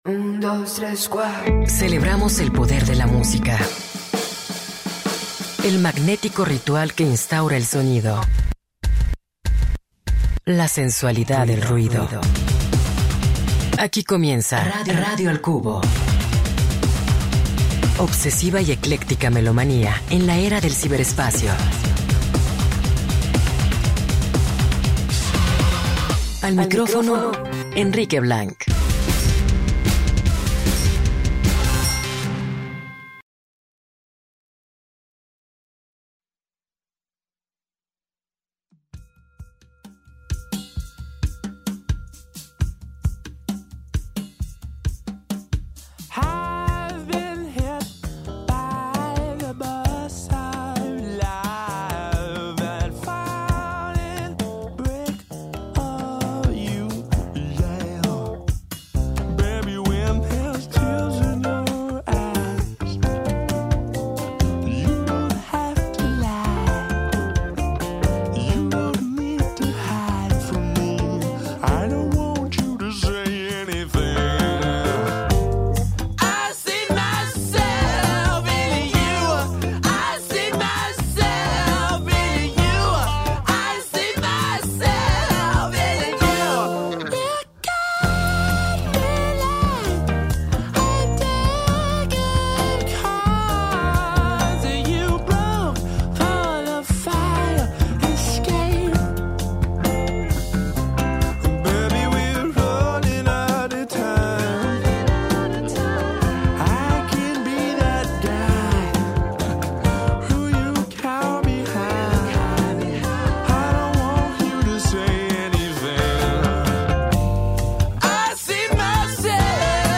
Escucha la entrevista en el 104.3FM